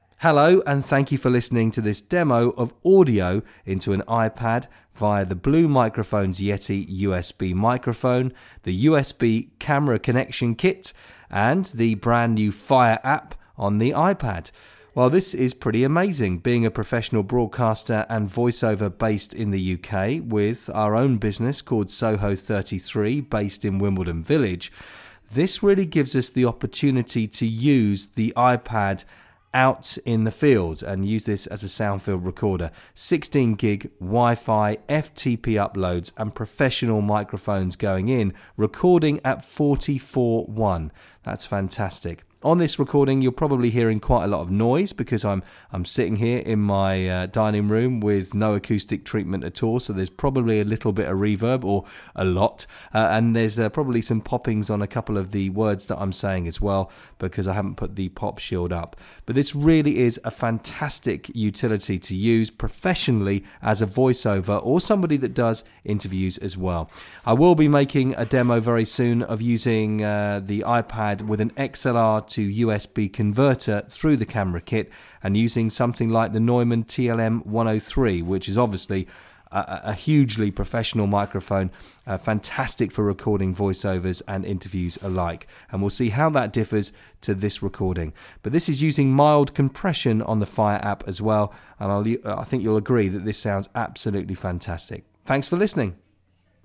Voice Over Recorded With iPad